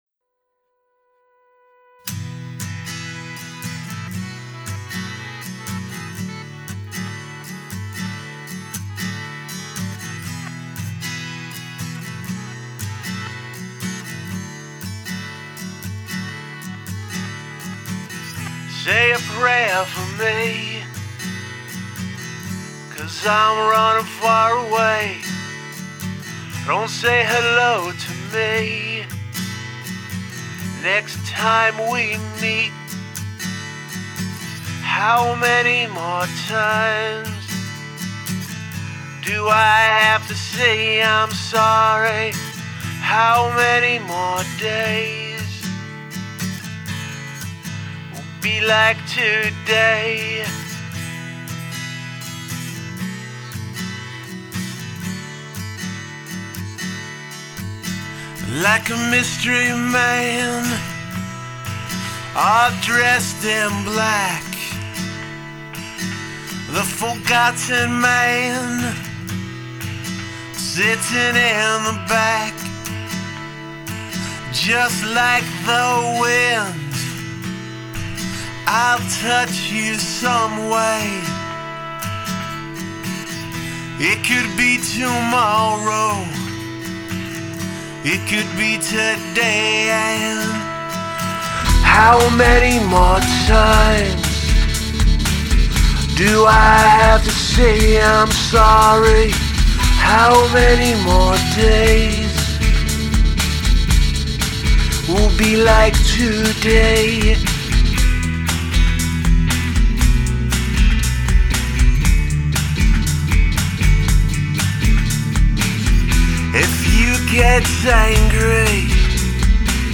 Recorded and mixed at LosNoiseFloorNY.
garagefolkrocknroll